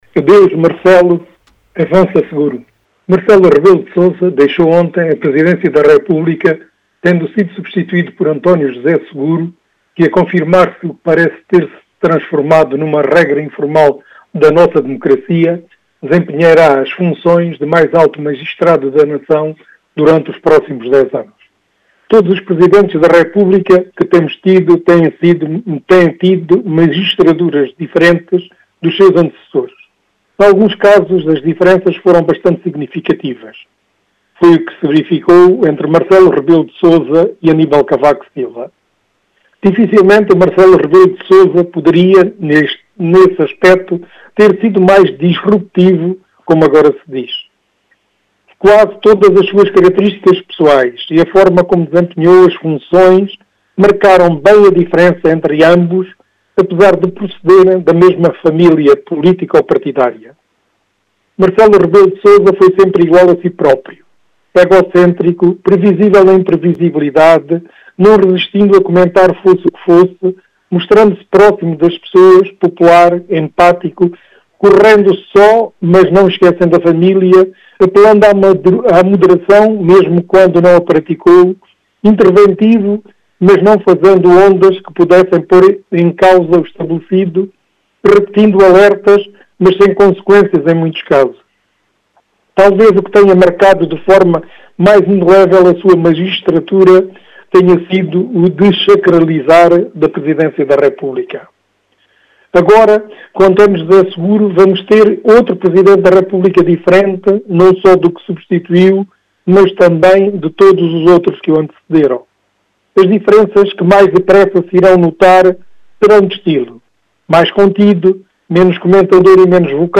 Crónica de Opinião